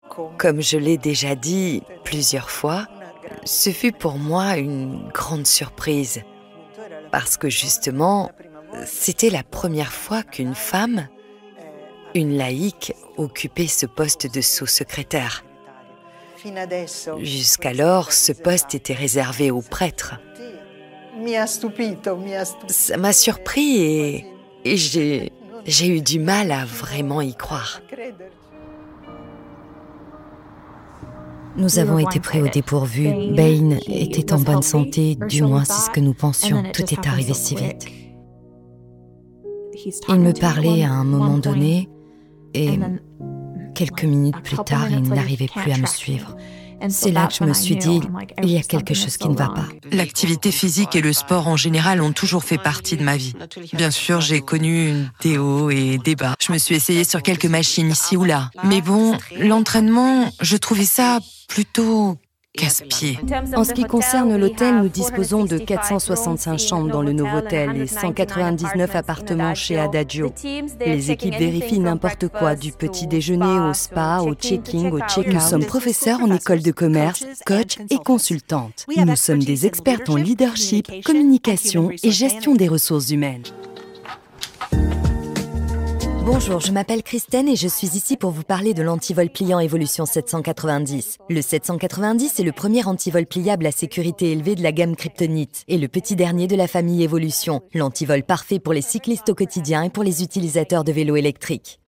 Tief, Natürlich, Unverwechselbar, Zugänglich, Vielseitig
Persönlichkeiten